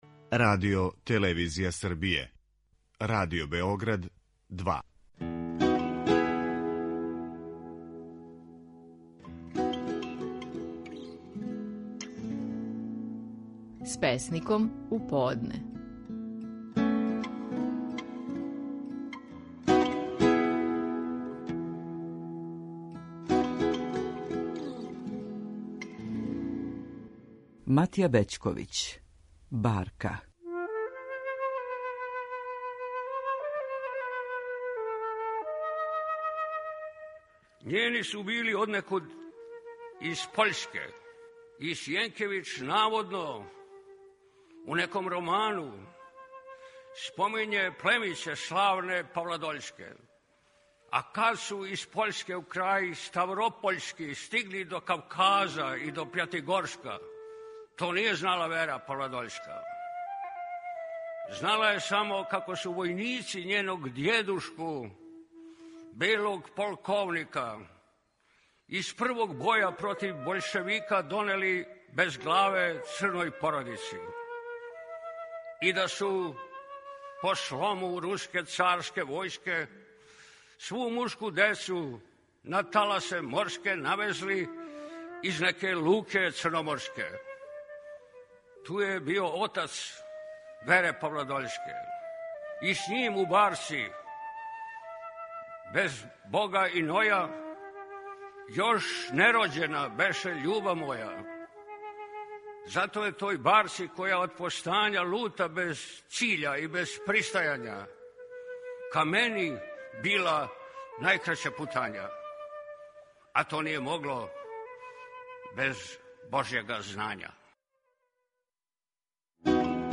Стихови наших најпознатијих песника, у интерпретацији аутора.
Матија Бећковић казује песму „Барка".